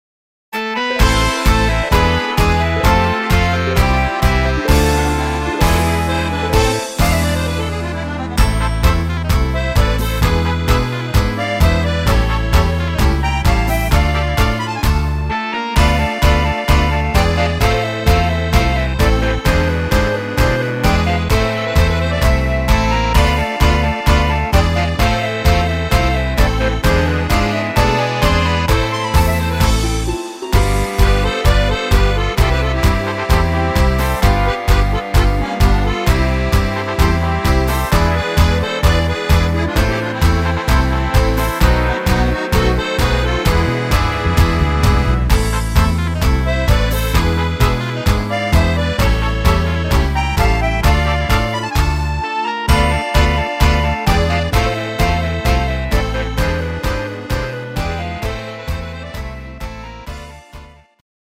(instr. Orchester)